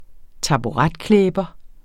Udtale [ -ˌklεːbʌ ]